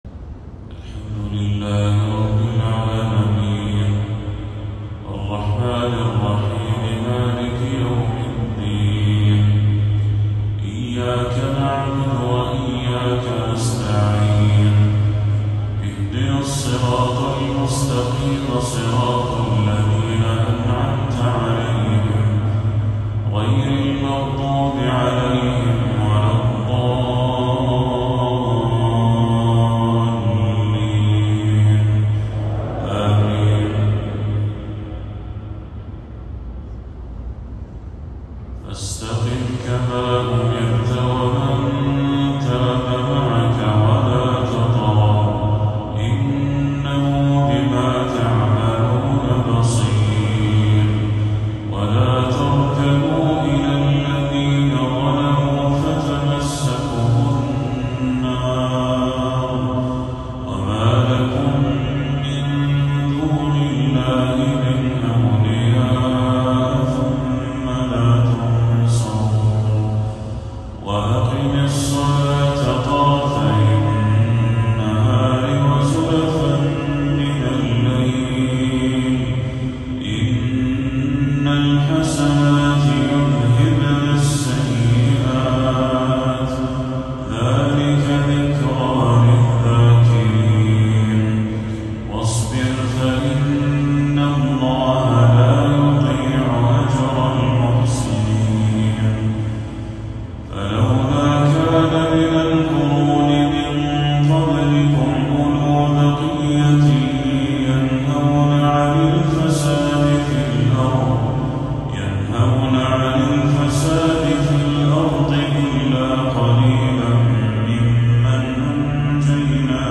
تلاوة لخواتيم سورتي هود والحجر للشيخ بدر التركي | عشاء 25 صفر 1446هـ > 1446هـ > تلاوات الشيخ بدر التركي > المزيد - تلاوات الحرمين